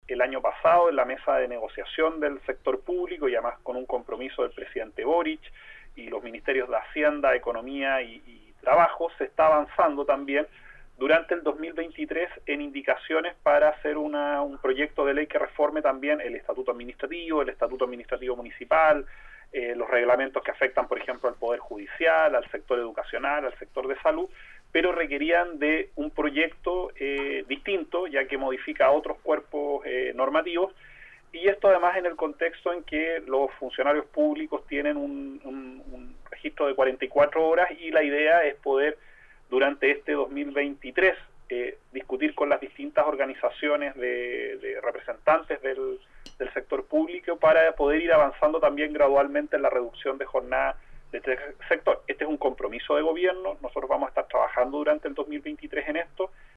En entrevista con Nuestra Pauta, Boccardo sostuvo que, de cara al tercer trámite de la iniciativa, en la Cámara de Diputadas y Diputados, «tenemos expectativas de que este proyecto sea respaldado mayoritariamente. Estamos haciendo el trabajo legislativo para ello, desplegados explicando a todas las bancadas el proyecto (…) La experiencia nos indica que hasta que no aparece el panel con las votaciones finales, no es bueno cantar victoria».